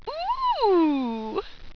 Index of /tactics/sfx/pain/sultry
ooooo.wav